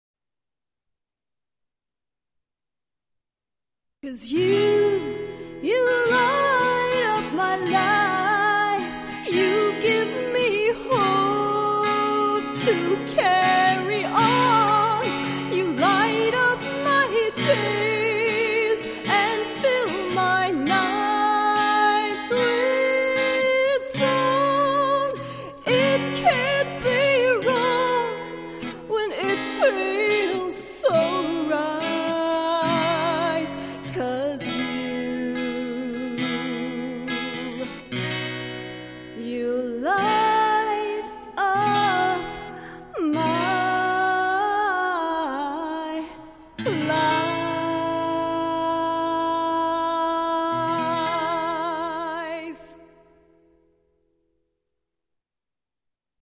Click the rose to hear her sing an excerpt from: